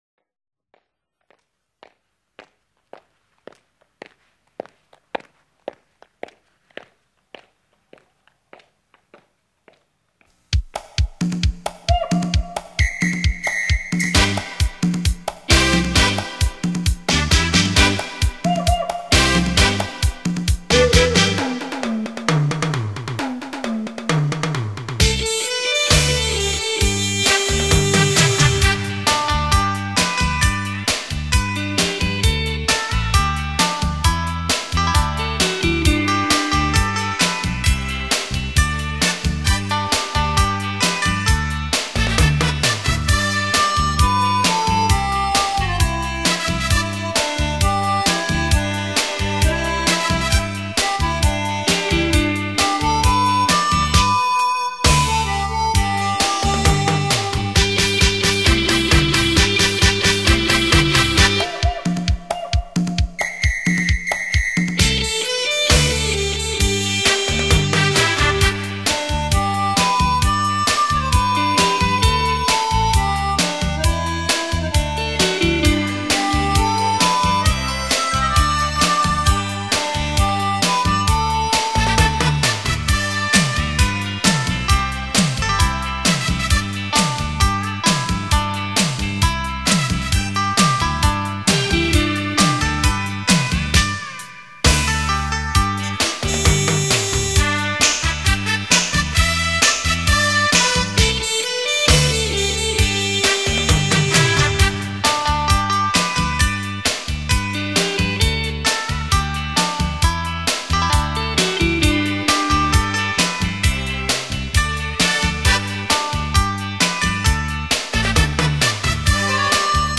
大师级乐手
LIVE飙技伴奏
雷射环绕效果